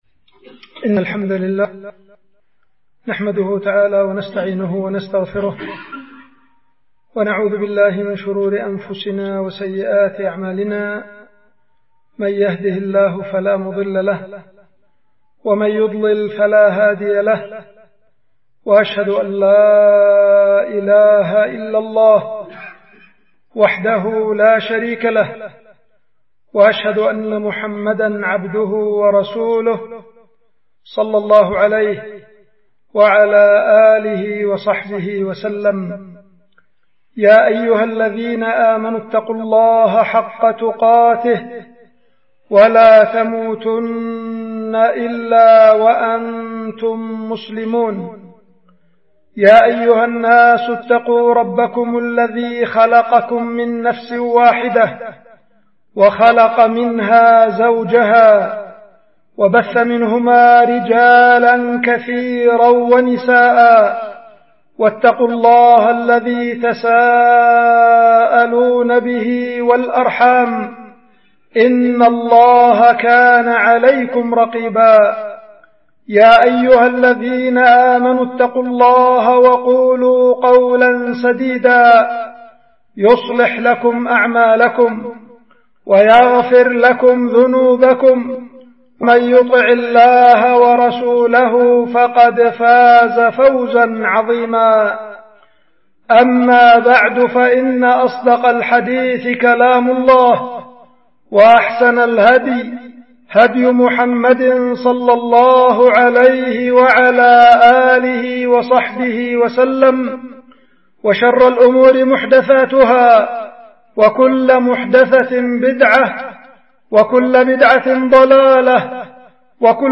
خطبة
القيت في مسجد العدنة- مديرية بعدان- إب-...